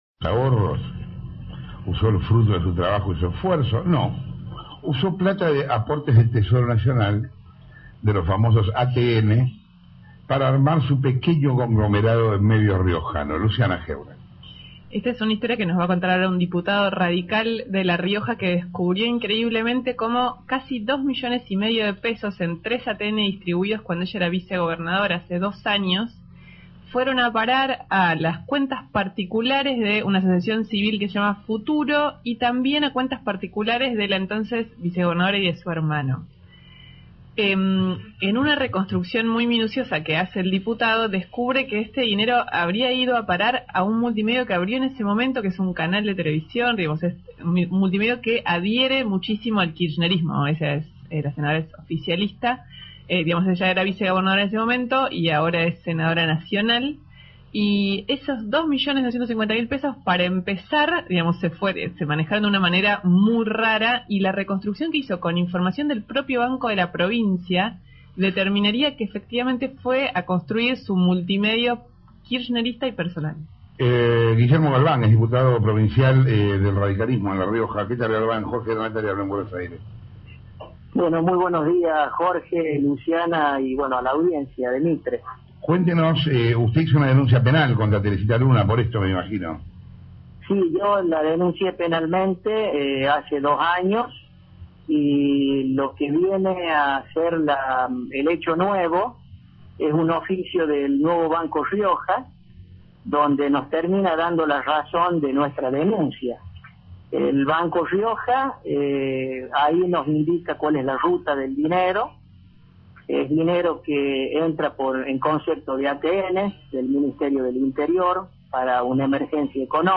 Guillermo Galván, diputado, por Radio Mitre